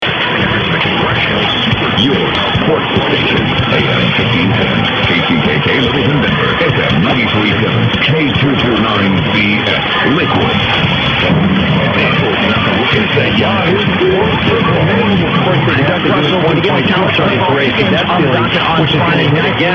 A FEW AUDIO CLIPS OF RECENT RECEPTION: